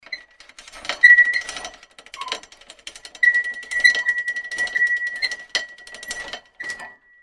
修理电报机的声音.mp3